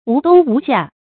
無冬無夏 注音： ㄨˊ ㄉㄨㄙ ㄨˊ ㄒㄧㄚˋ 讀音讀法： 意思解釋： 無論冬天還是夏天。指一年四季從不間斷。